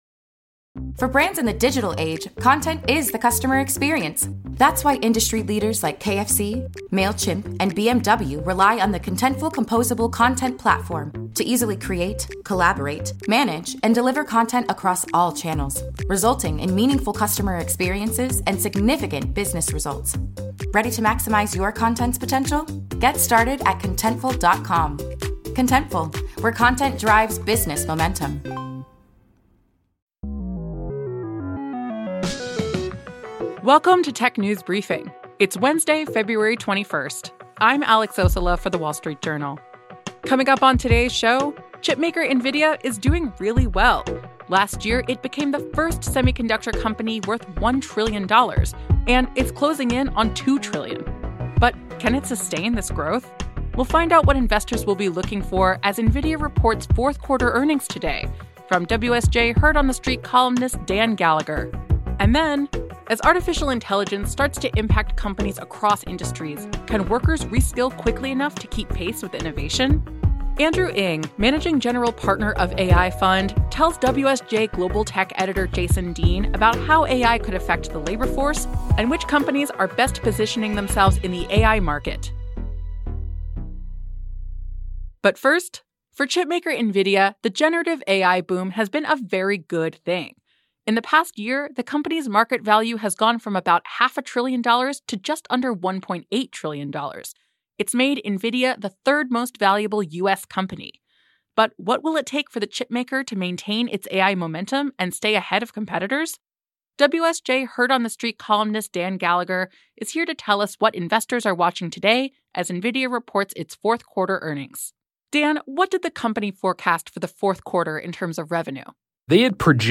at the WSJ CIO Network Summit about how AI will affect the labor force, and which companies are positioning themselves the best in the AI market.